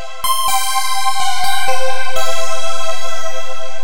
Звук синтезатора: Underground (Synth Loop)
Тут вы можете прослушать онлайн и скачать бесплатно аудио запись из категории «Big Beat».